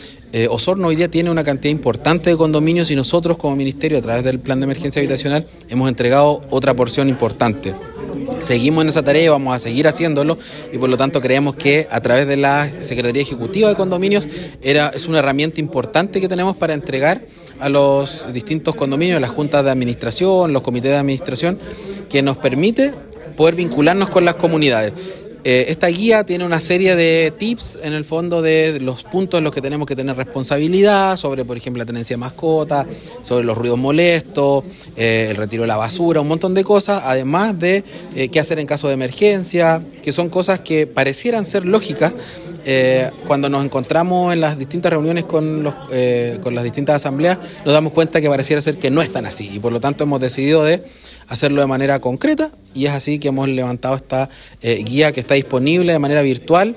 El Seremi de Vivienda y Urbanismo, enfatizó en que esta guía no viene a reemplazar a los reglamentos internos de cada administración de copropiedades, sino que colabora en aspectos que no habían sido abordados.